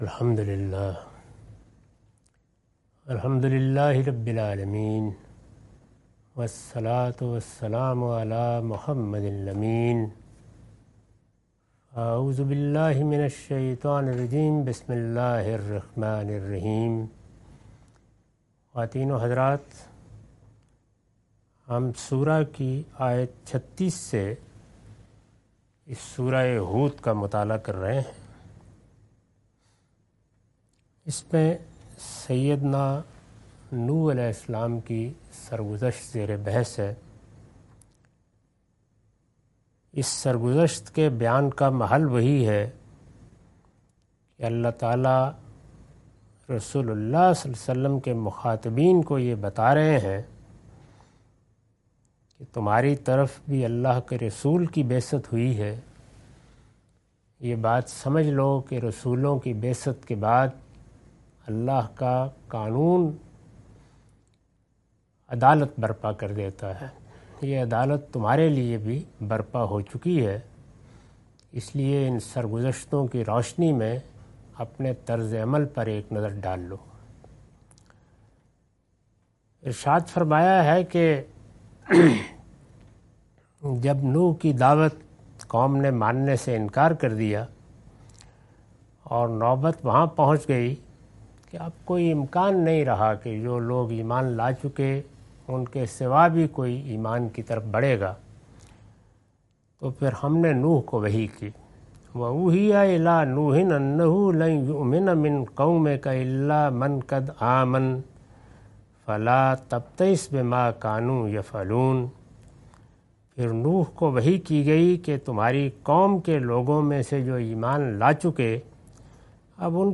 Surah Hud- A lecture of Tafseer-ul-Quran – Al-Bayan by Javed Ahmad Ghamidi. Commentary and explanation of verses 36-41.